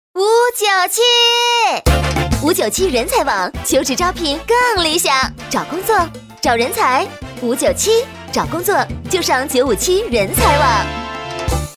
女国83_动画_童声_小女孩-新声库配音网